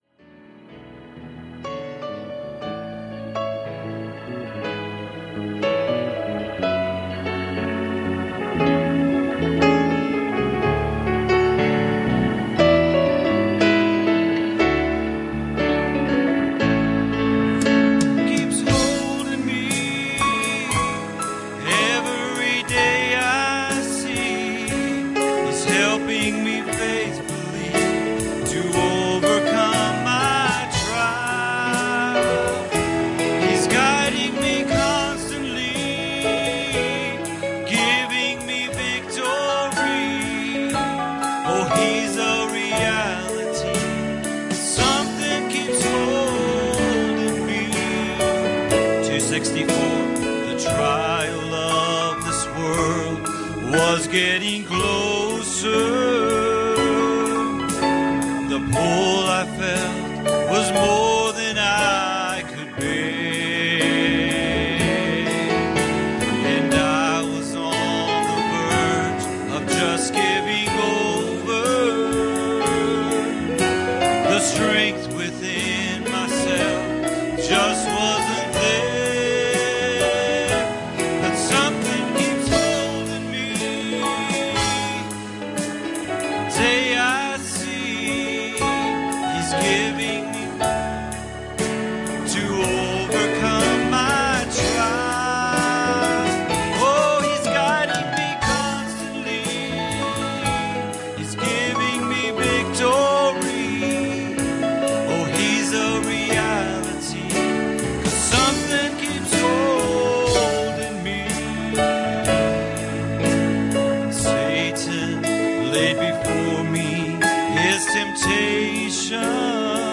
Series: Sunday Morning Services
Service Type: Sunday Morning